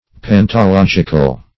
Pantological \Pan`to*log"ic*al\, a. Of or pertaining to pantology.
pantological.mp3